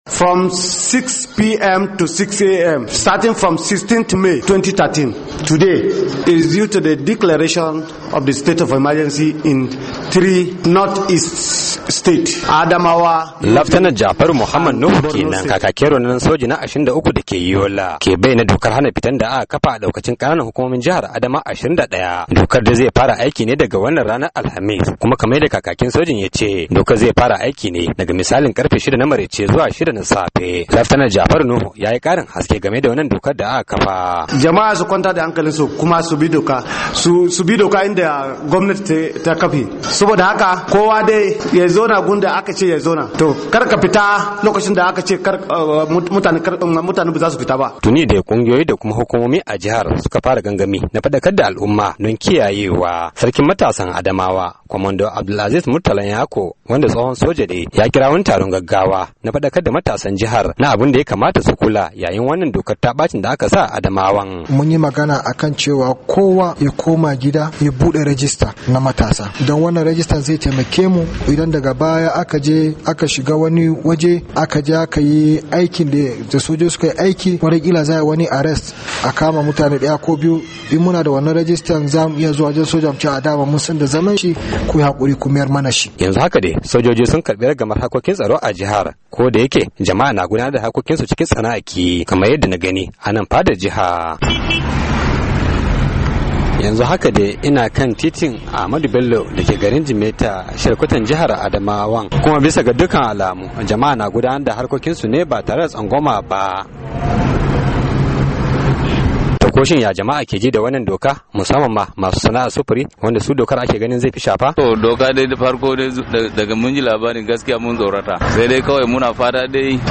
Rahoton